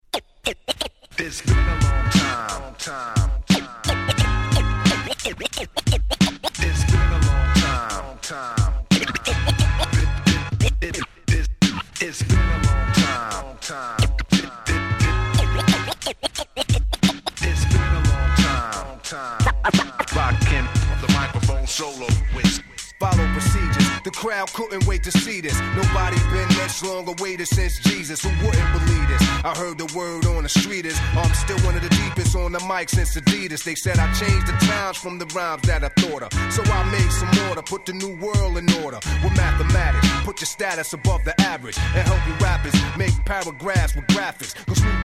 90's Boom Bap